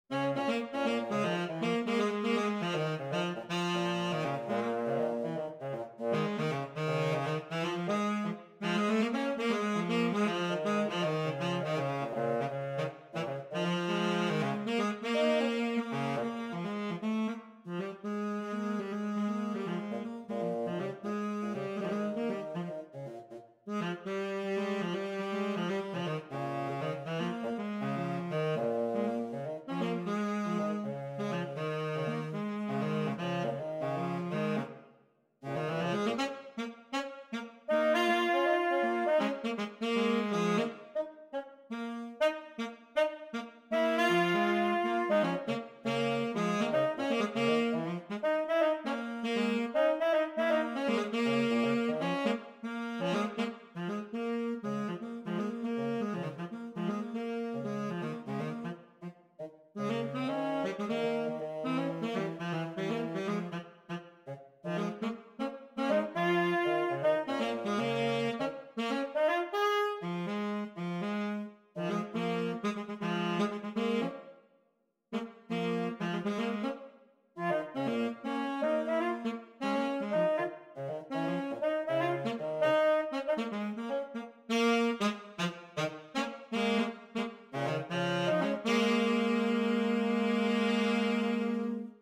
Gattung: Für 2 Tenorsaxophone